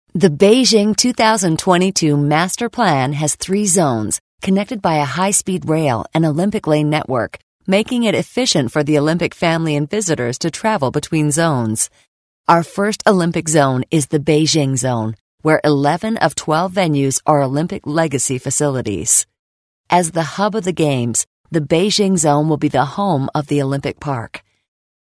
美式英语配音